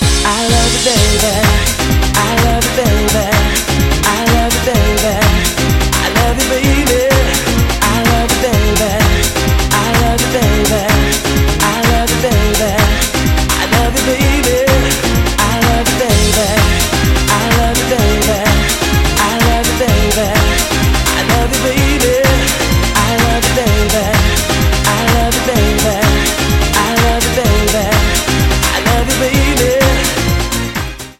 • Качество: 128, Stereo
евродэнс